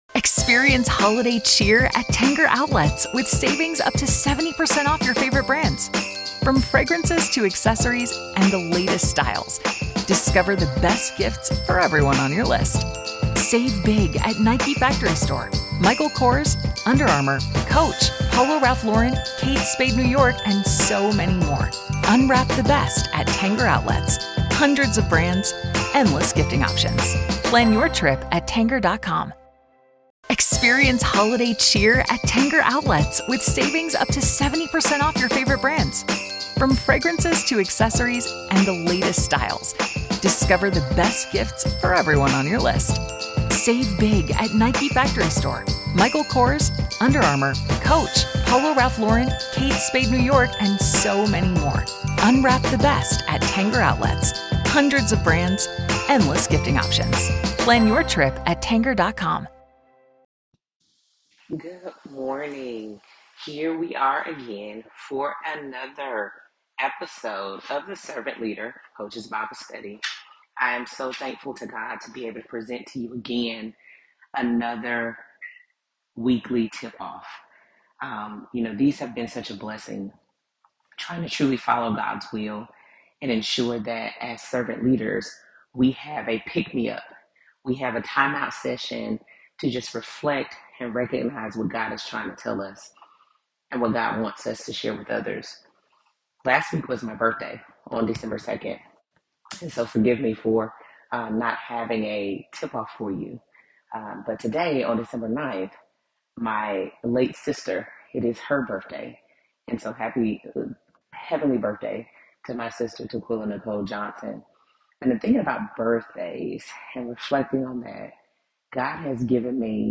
Athletes, Coaches, and Leaders give their counsel on what Servant Leadership looks like in their positions, and how God has laid the foundation. Also, hear testimonies of faith, overcoming, and how God directs the paths of many athletic professionals.